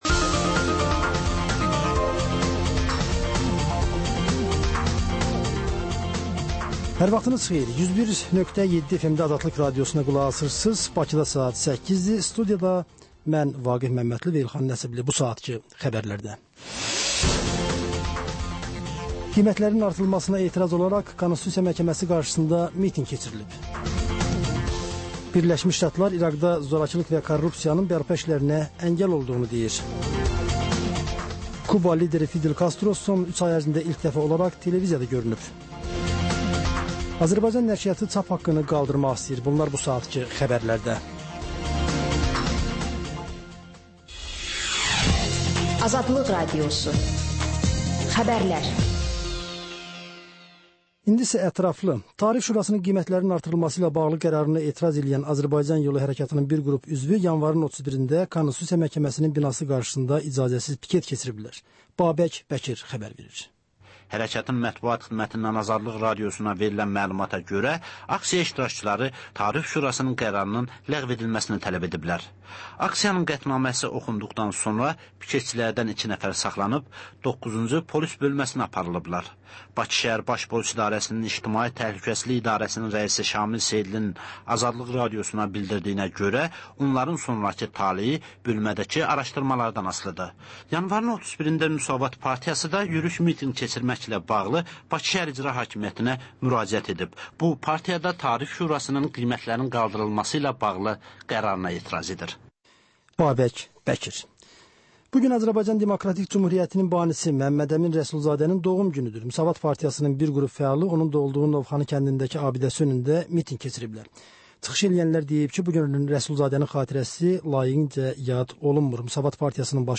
Xəbər, reportaj, müsahibə.